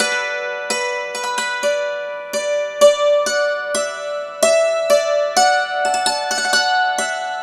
Dulcimer14_129_G.wav